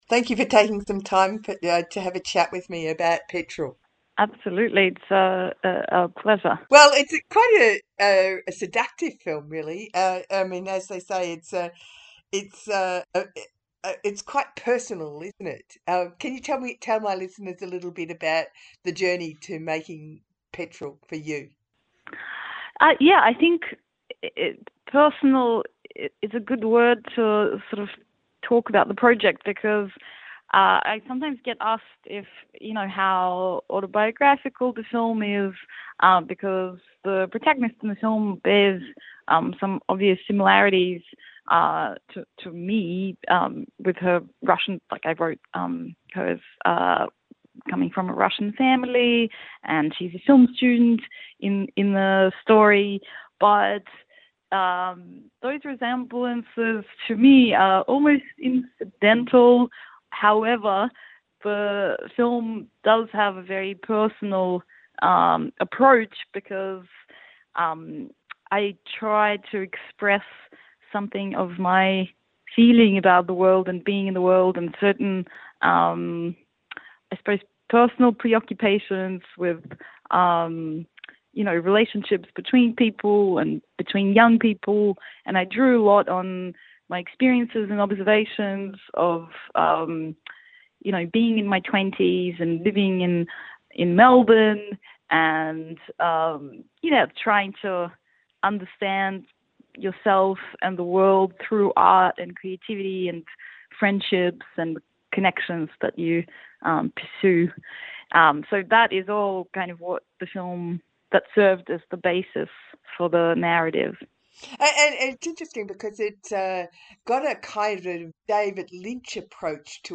Radiothon Show featuring Petrol interview